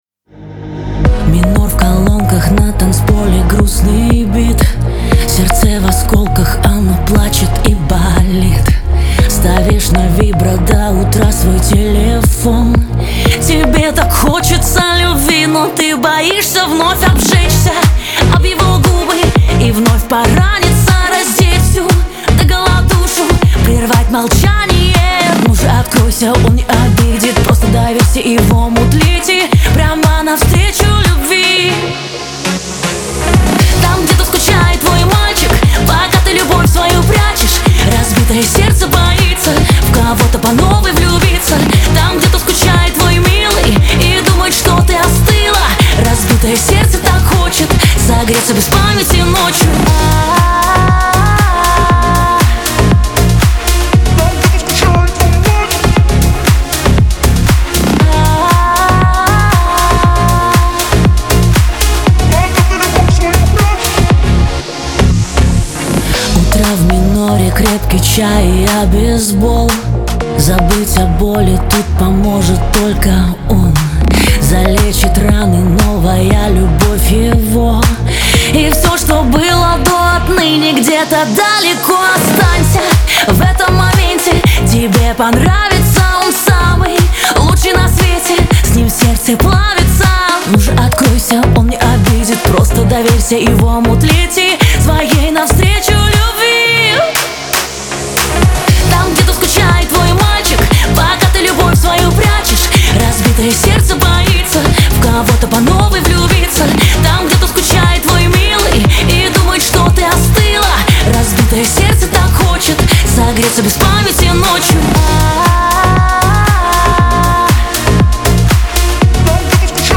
эстрада